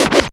DIRTY SCRATC.wav